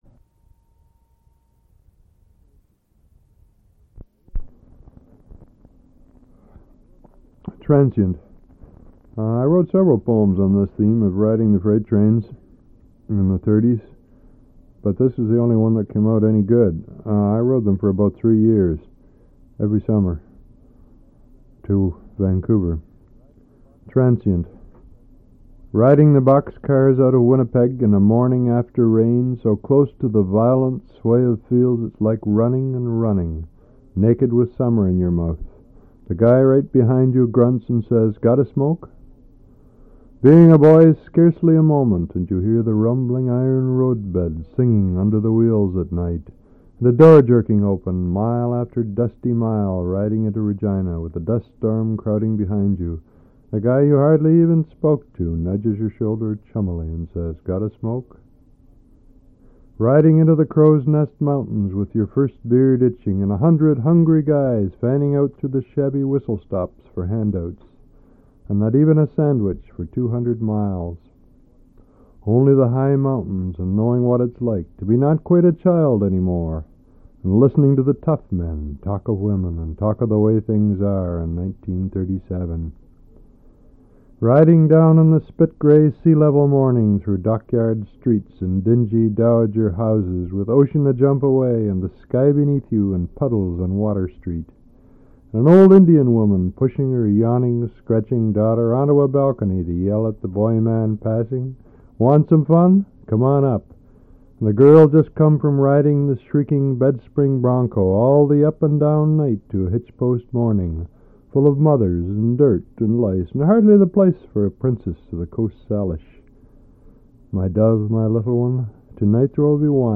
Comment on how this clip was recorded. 1/4"' Reel-to-Reel Tape